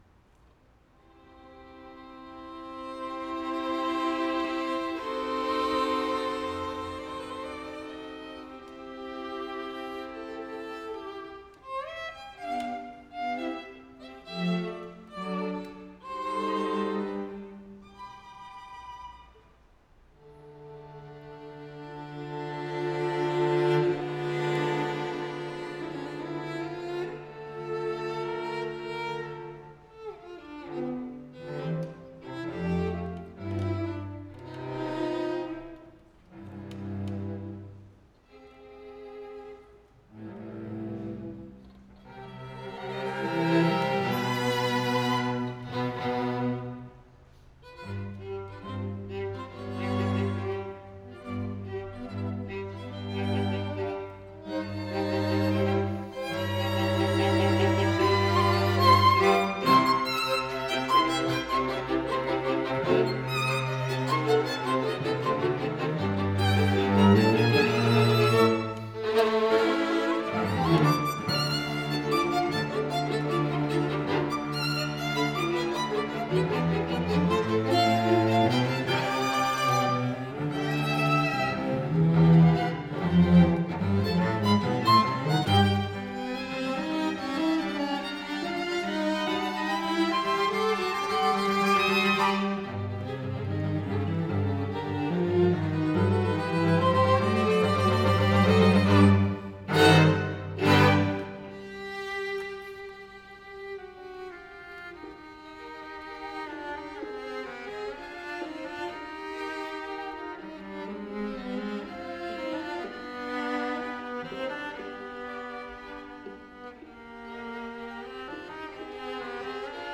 the students
Chamber Groups
Allegro ma non troppo